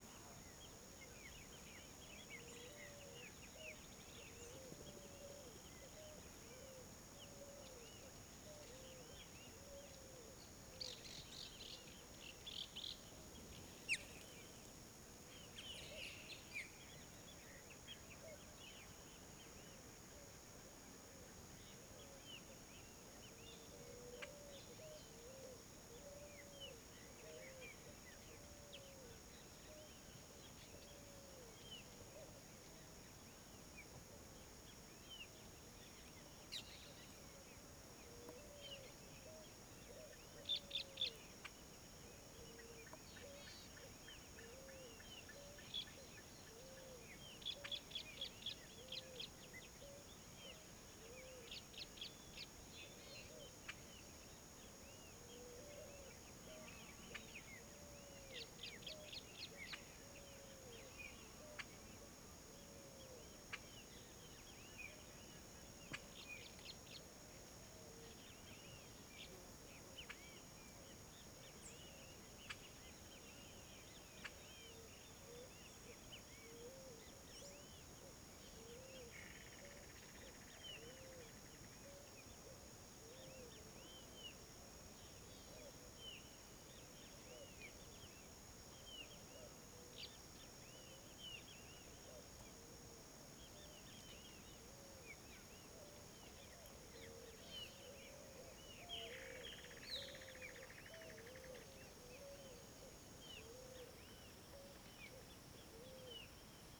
CSC-05-060-LE - Ambiencia sertao de urucuia em fim de tarde com pomba asa branca e outros passaros.wav